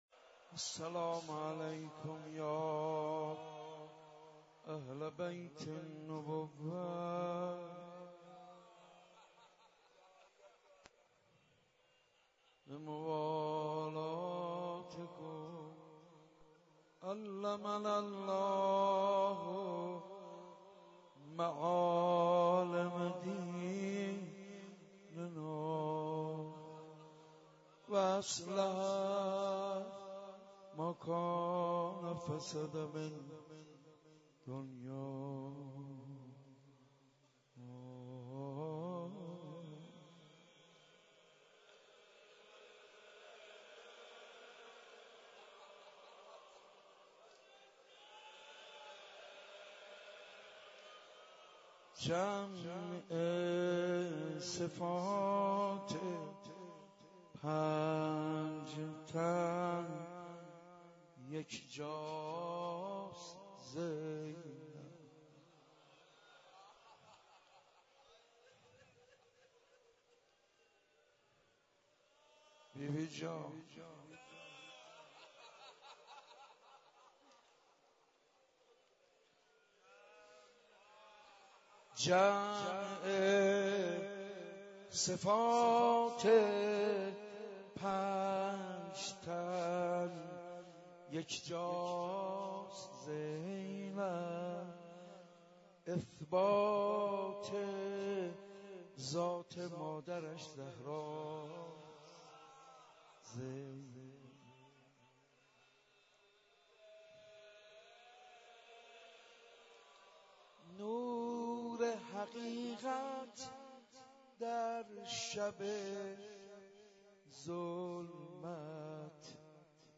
شب عاشورا محرم95/مسجد ارک تهران